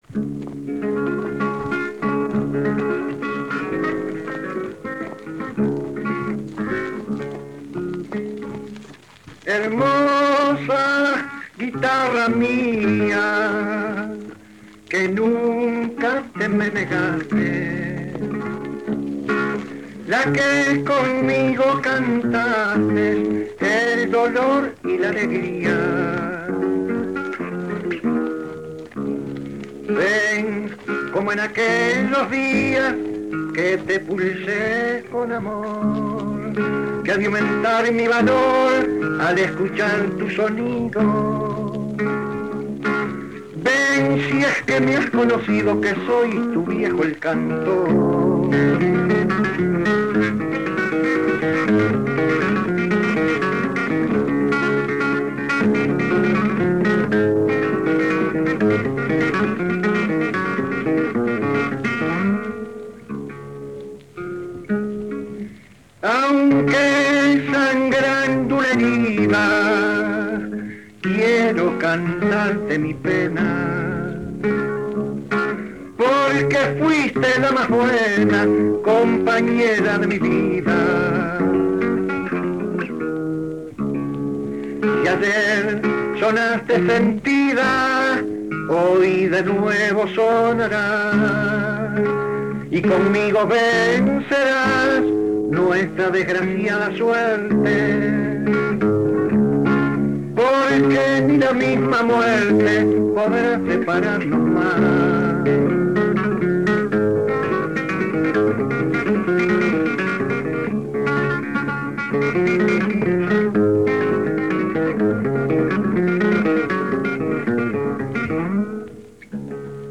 canto y guitarra
Formato original de la grabación: disco de acetato Audiodisc de 25 cm de base metálica a 78 rpm